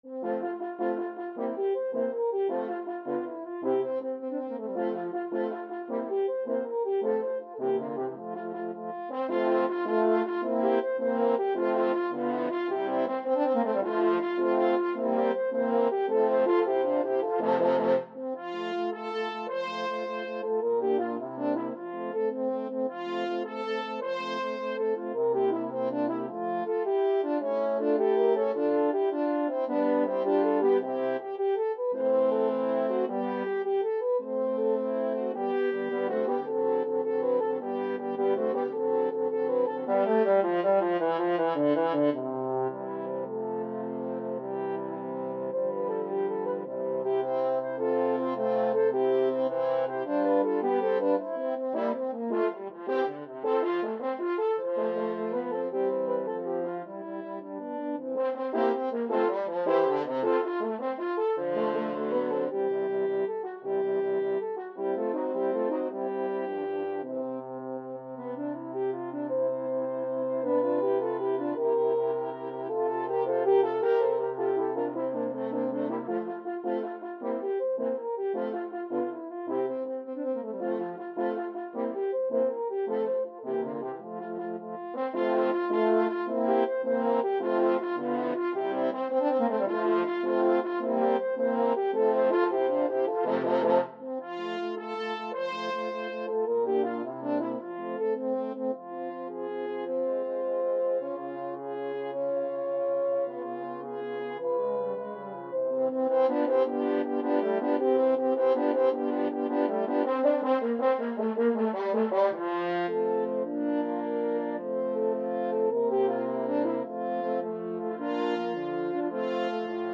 6/8 (View more 6/8 Music)
Allegro vivace .=106 (View more music marked Allegro)
Classical (View more Classical French Horn Quartet Music)